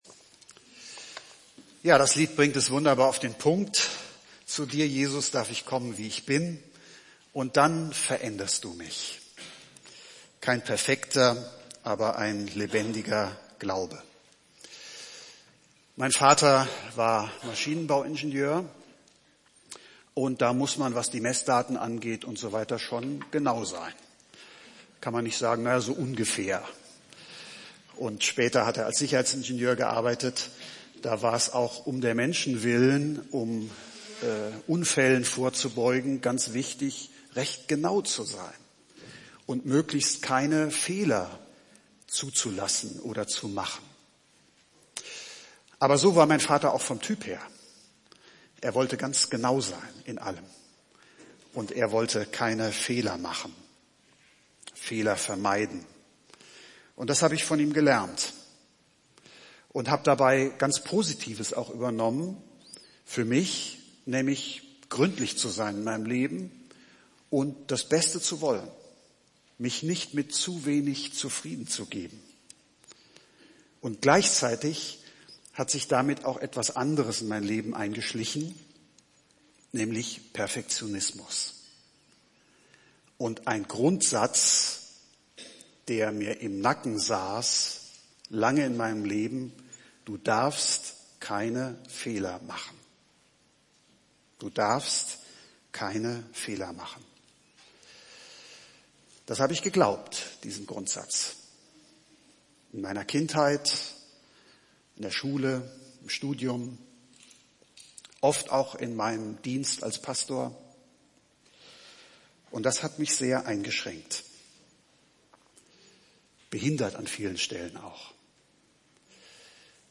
Predigttexte: Philipper 1,6 + 3,12